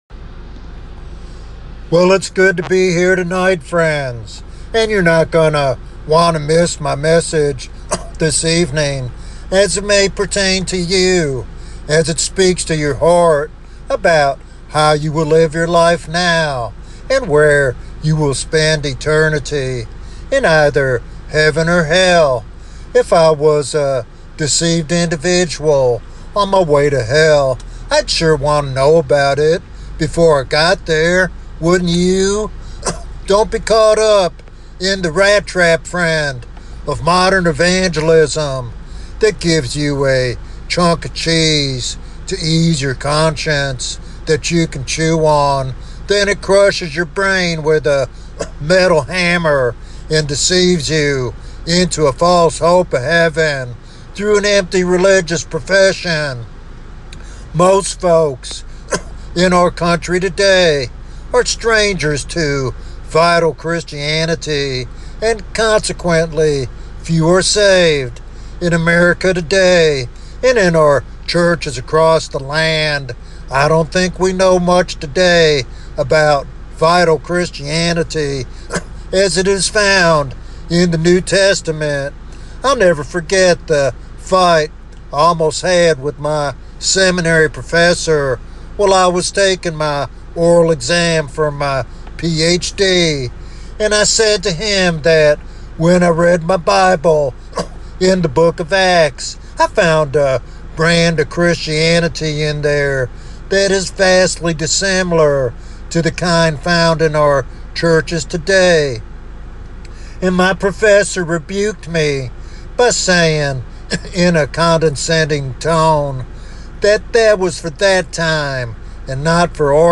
This sermon is a passionate call to experience the true power and presence of Jesus today.